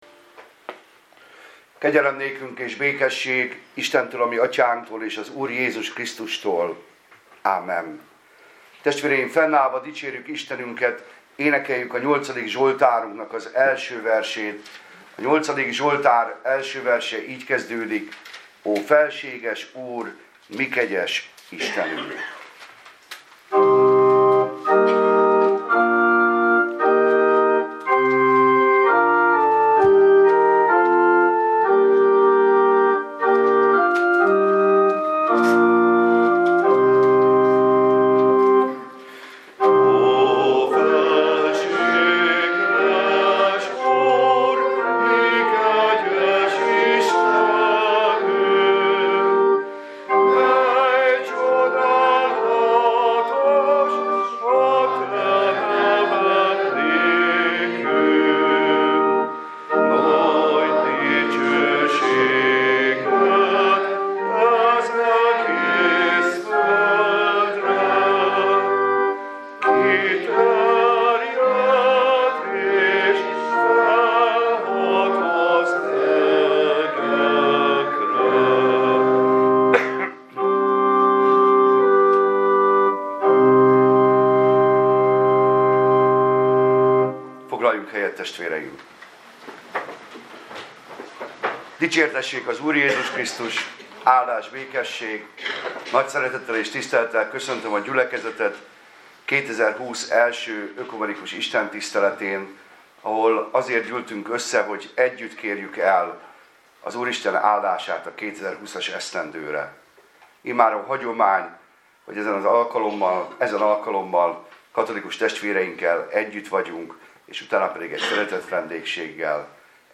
ökumenikus újévi istentisztelet